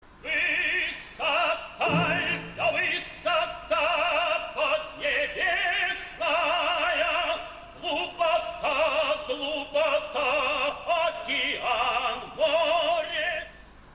Назовите оперу Римского-Корсакова.